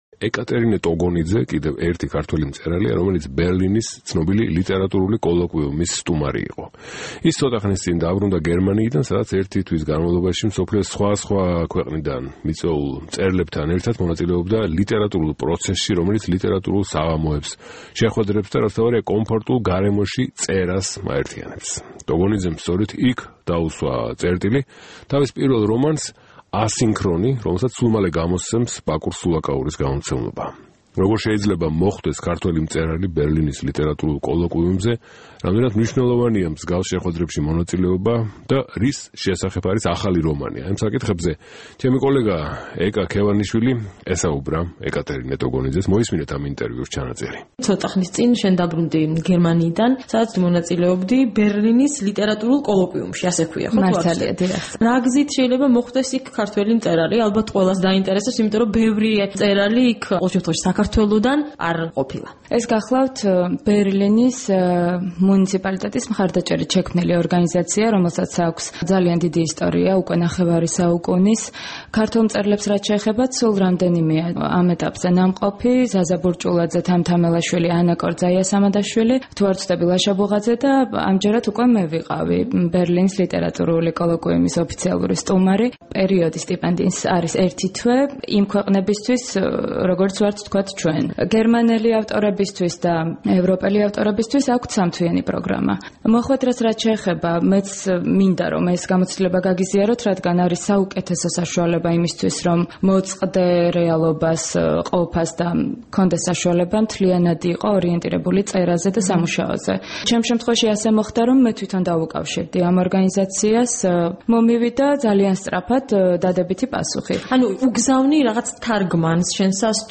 ინტერვიუს გთავაზობთ.